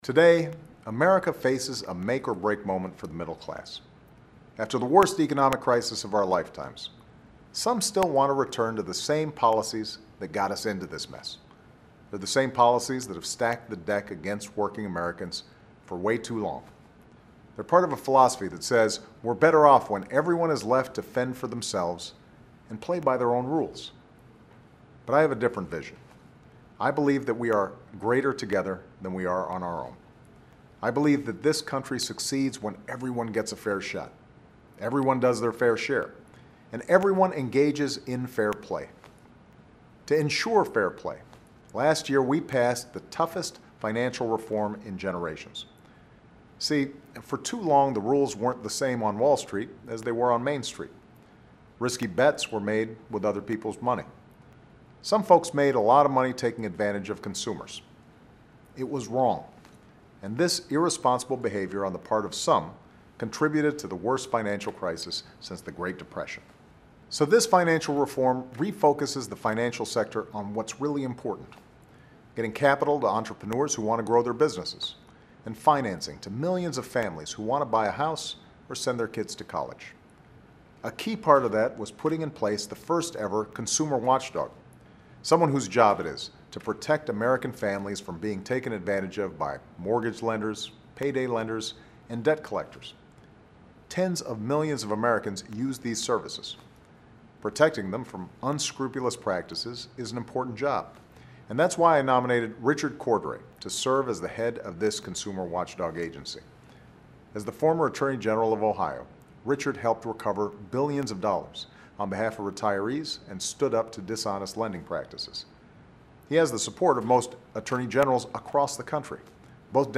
演讲听力材料12.11
Remarks of President Barack Obama